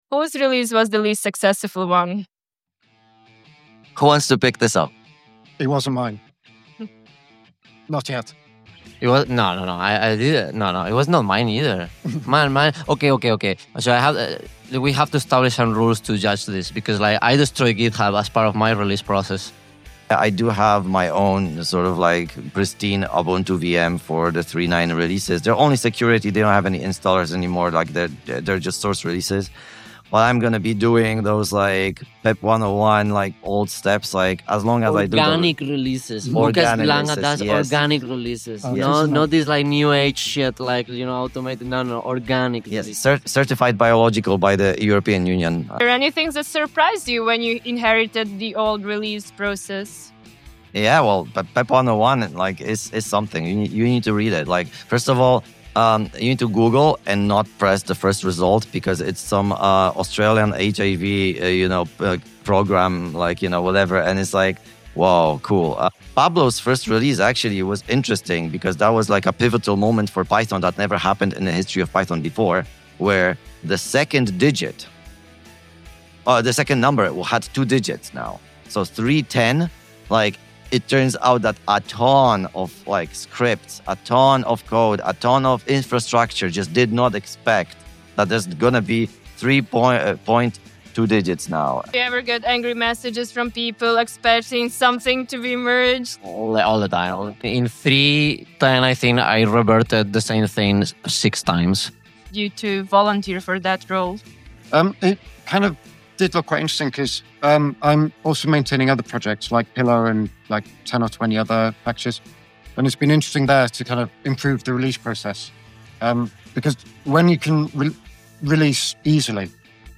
Sneak peak of the episode about CPython release. Have you ever wondered how a CPython release works?